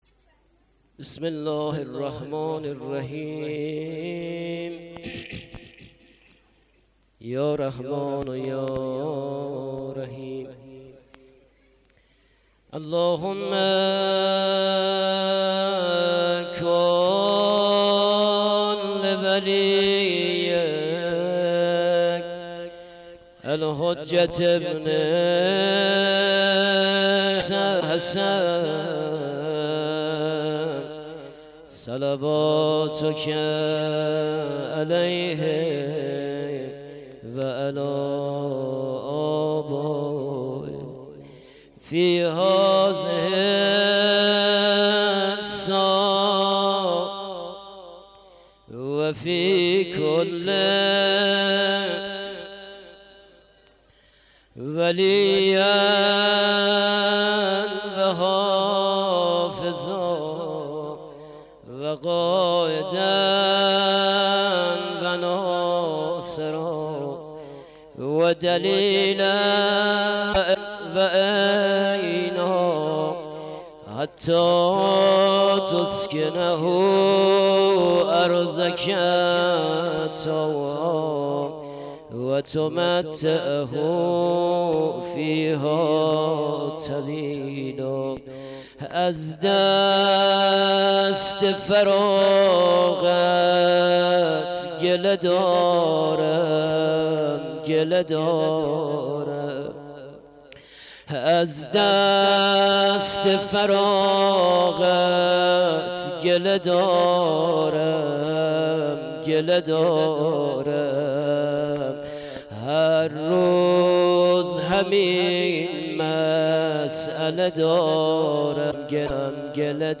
• شب 11 محرم 93 هیآت ثارالله.mp3
شب-11-محرم-93-هیآت-ثارالله.mp3